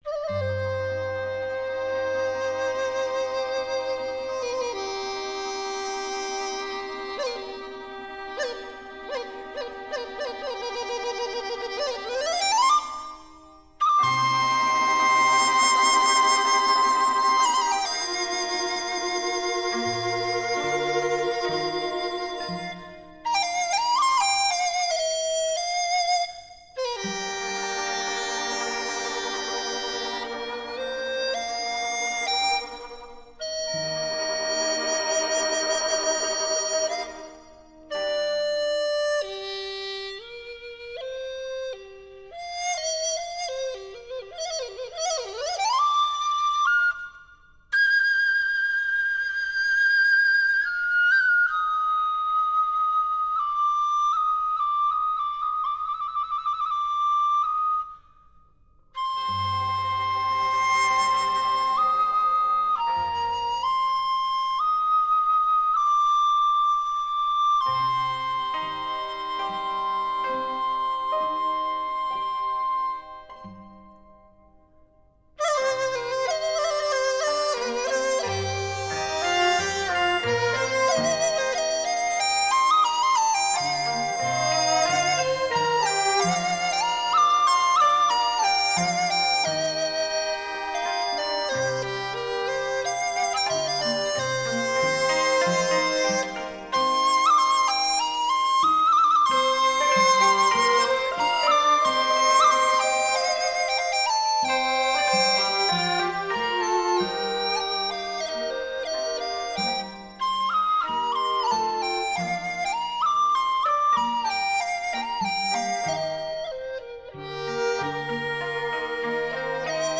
音乐类型: 民乐
活泼秀丽 含蓄深情悠扬委婉 意境秀美 韵味深长